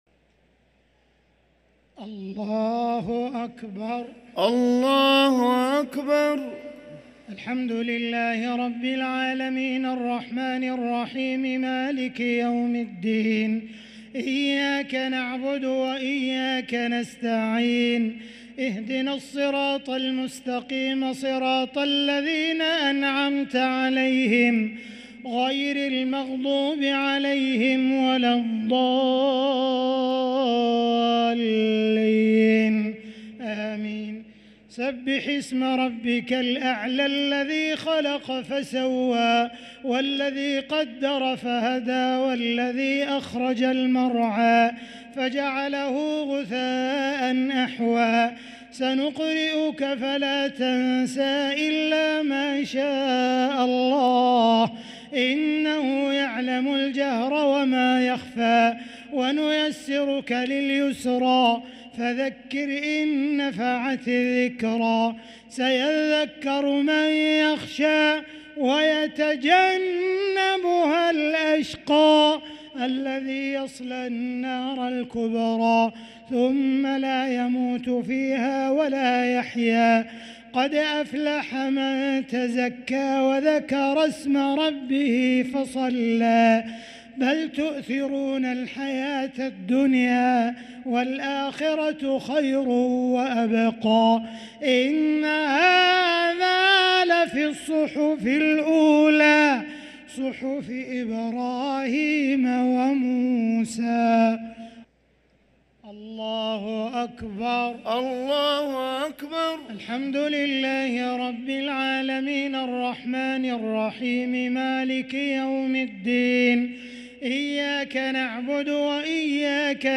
صلاة الشفع و الوتر ليلة 1 رمضان 1444هـ | Witr 1st night Ramadan 1444H > تراويح الحرم المكي عام 1444 🕋 > التراويح - تلاوات الحرمين